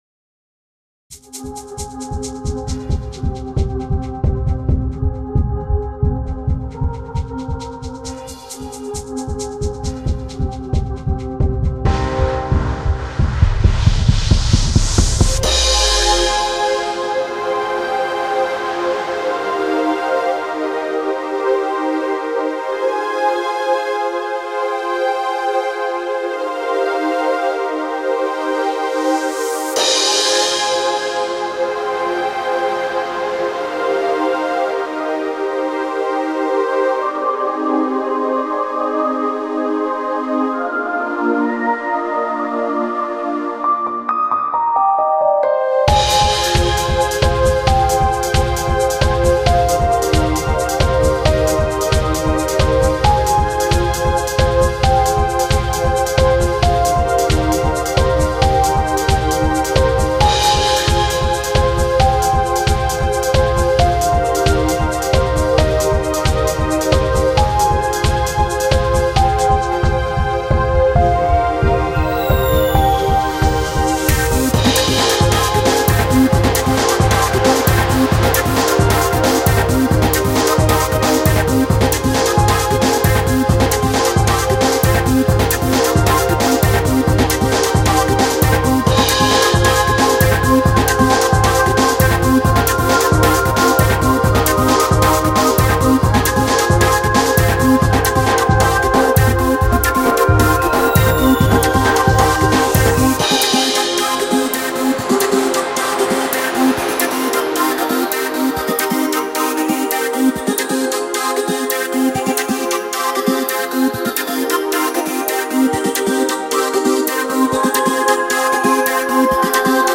メロディックトランスの練習用？